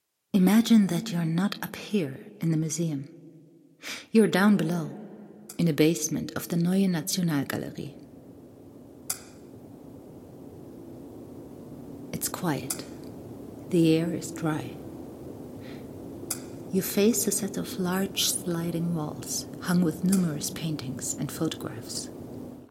Female
Approachable, Bright, Character, Confident, Conversational, Corporate, Engaging, Friendly, Natural, Reassuring, Smooth, Versatile, Warm
EN-Commercial-La Roche Posay.mp3
Microphone: Rode NT1
Audio equipment: Focusrite Scarlett audio interface, pop filter, soundproof cabin